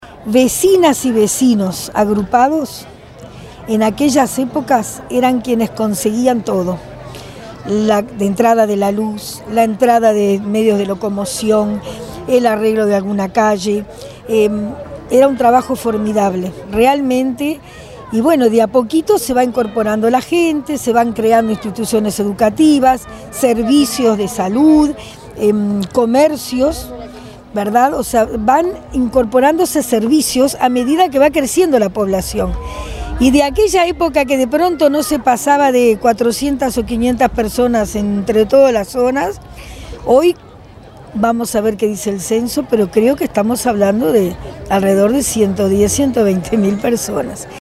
A orillas del arroyo Pando en El Pinar, Ciudad de la Costa celebró sus 29 años con la colocación de un escenario en el que hubo artistas locales, nacionales, una feria de emprendedores y diversas autoridades.
La Alcaldesa del Municipio de Ciudad de la Costa, Mtra. Sonia Misirián, enfatizó el significativo crecimiento de la localidad y recordó que décadas atrás “eran tan solo algunos solares poblados y su crecimiento ha sido desmedido en los últimos años”.